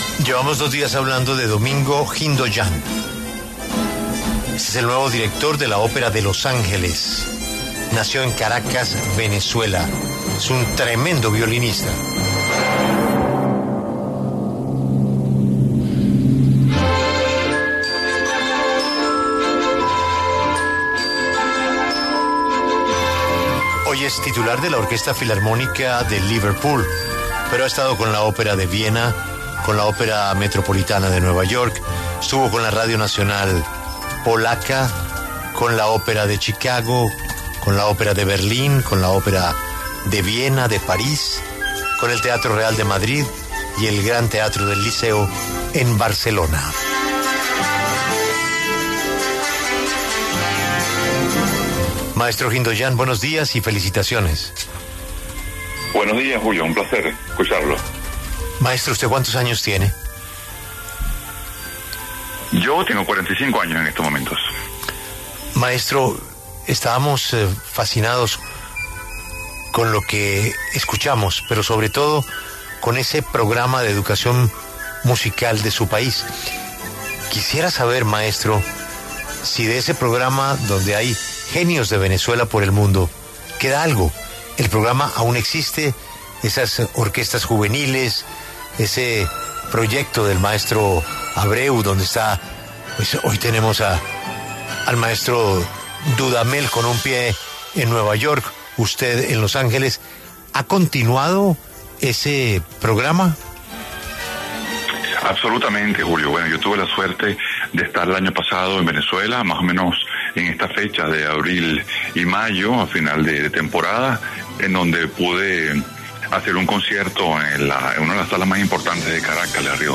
El maestro Domingo Hindoyan, nacido en Caracas, Venezuela, quien fue nombrado como nuevo director musical de la Ópera de Los Ángeles a partir del 2026, pasó por los micrófonos de La W y entregó detalles sobre el reconocido programa venezolano de educación musical ‘El Sistema’.